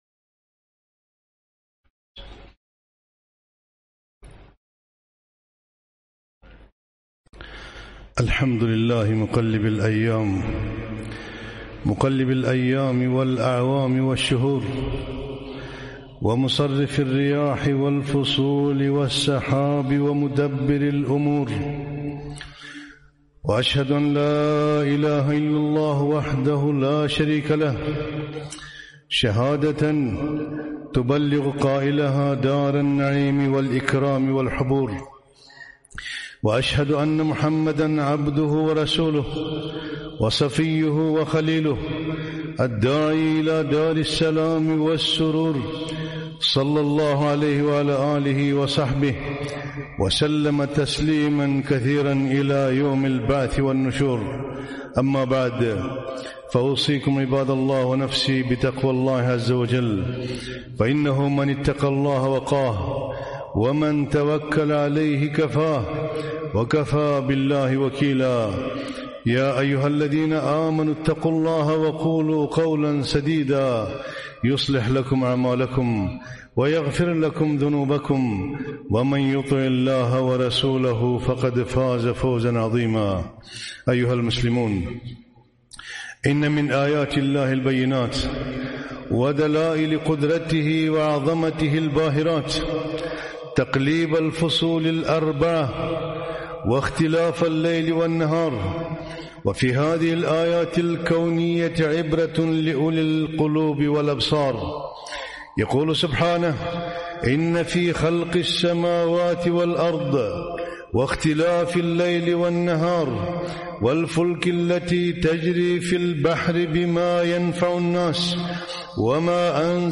خطبة - عظات وعبر من شدة الحر